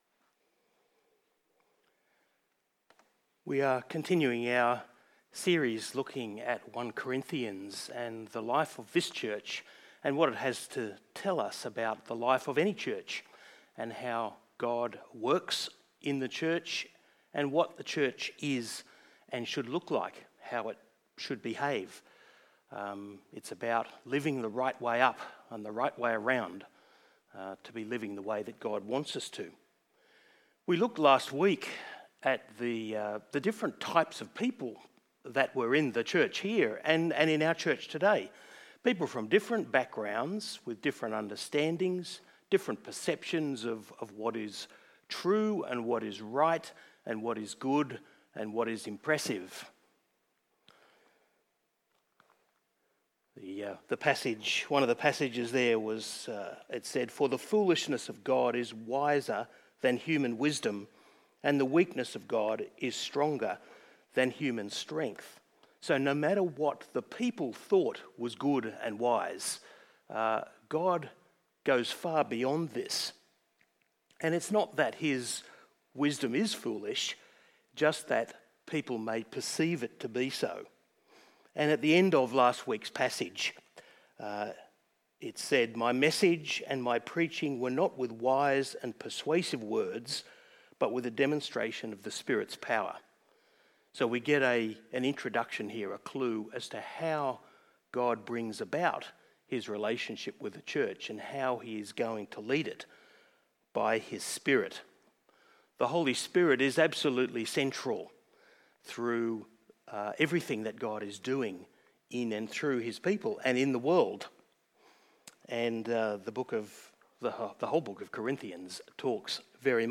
KPCC Sermons | Kings Park Community Church